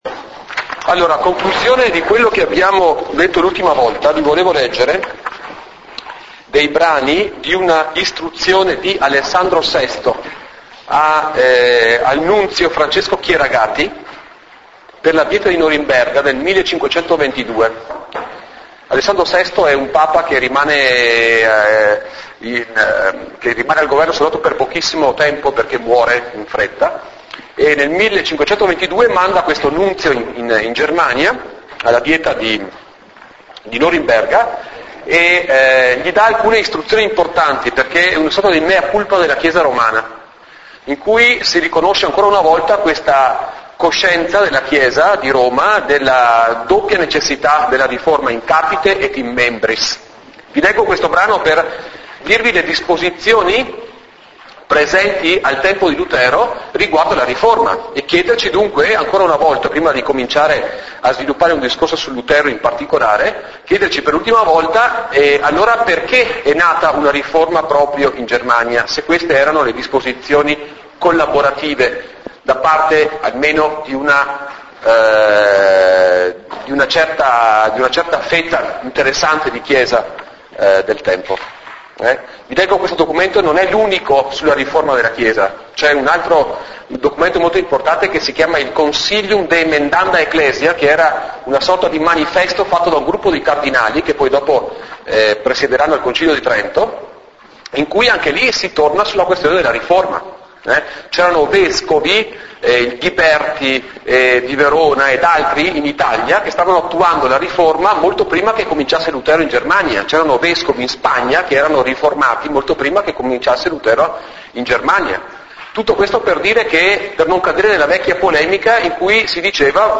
In Italian: 04� Lezione registrata: "Origini della Riforma"